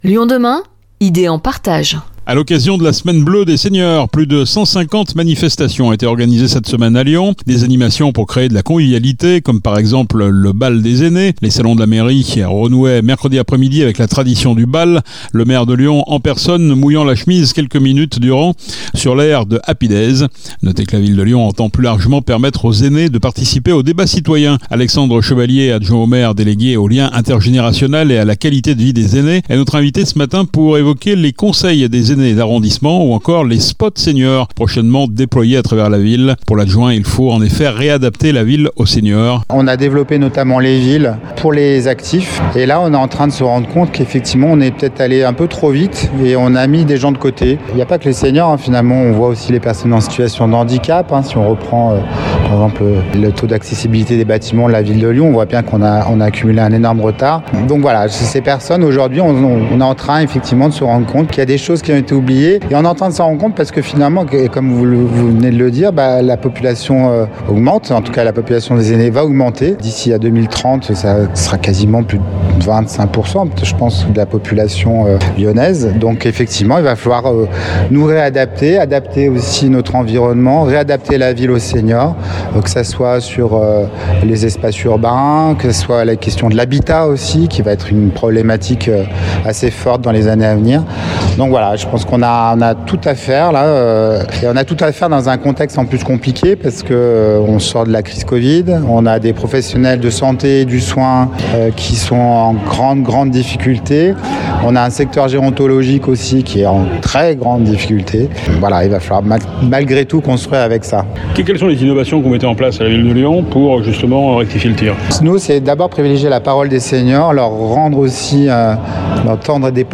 Pour en savoir plus, nous avons rencontré Alexandre Chevalier, adjoint au maire, délégué au lien intergénérationnel et à la qualité de vie des aînés.